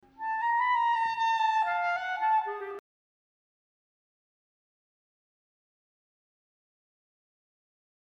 One more example of timbre contrast in the first movement occurs in measure 38, where the D-flat should be fingered in the following manner to preserve the bright quality of the line (Example #56).
This helps to vividly contrast the thick timbres of measure 37.